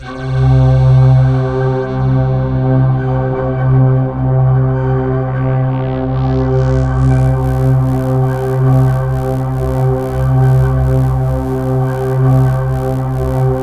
Index of /90_sSampleCDs/USB Soundscan vol.13 - Ethereal Atmosphere [AKAI] 1CD/Partition C/04-COBRA PAD